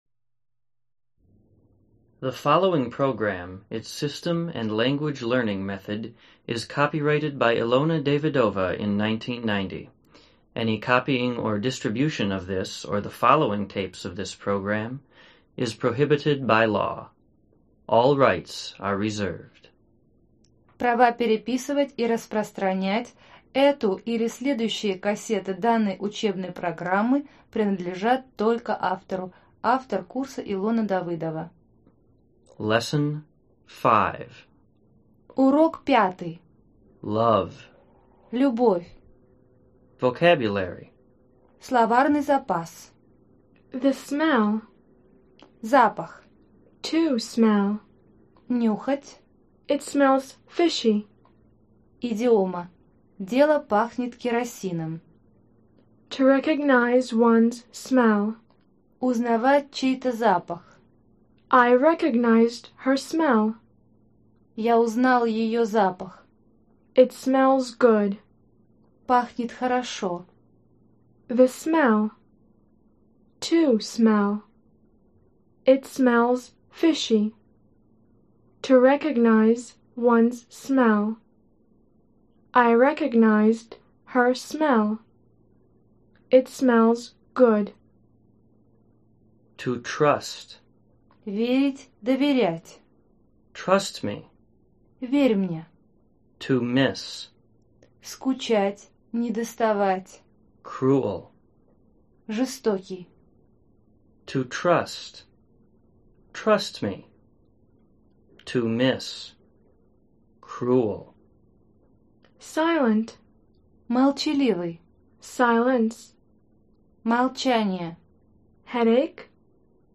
Аудиокнига Разговорно-бытовой английский. Диск 5: Любовь | Библиотека аудиокниг